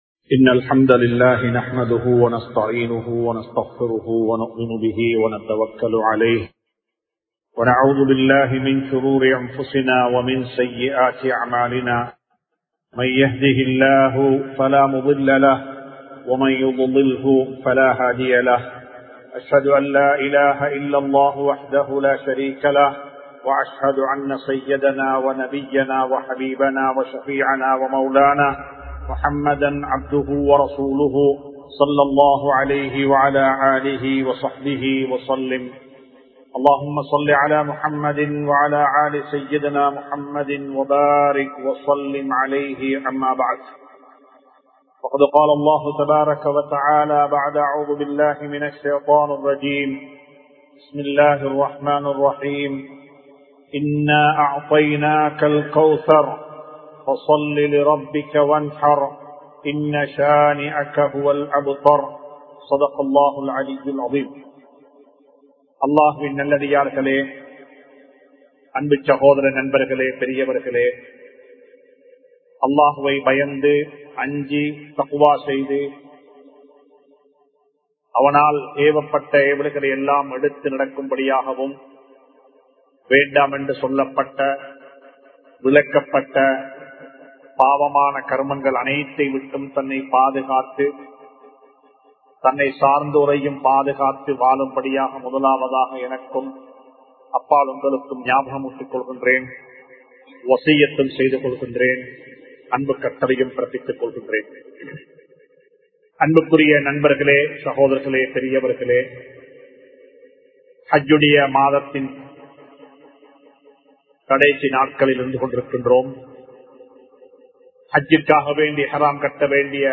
பெருநாளை எவ்வாறு கொண்டாட வேண்டும்? | Audio Bayans | All Ceylon Muslim Youth Community | Addalaichenai